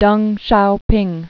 (dŭng shoupĭng, shyou-) also Teng Hsiao-ping (tŭng shyoupĭng, dŭng) 1904-1997.